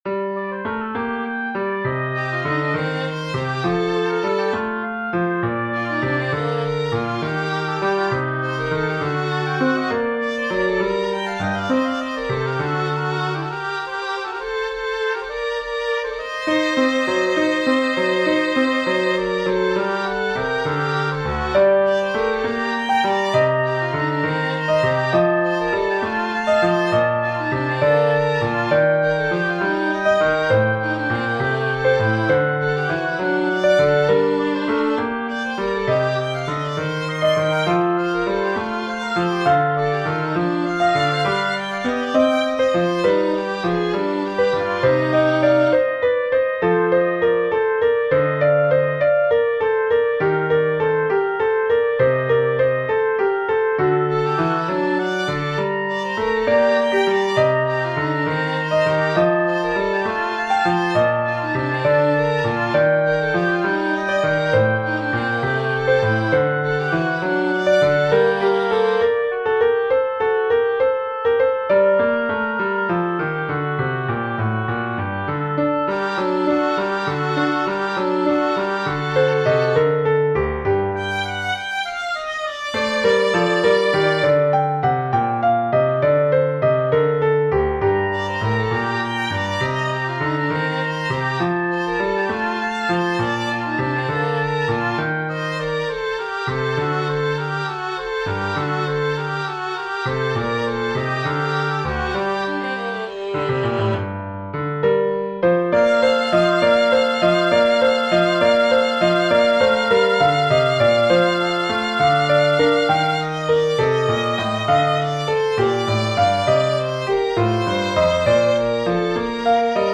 Haendel, G. F. Genere: Barocco "Apollo e Dafne" (HWV 122) è una delle più ambiziose cantate profane di Georg Friedrich Händel.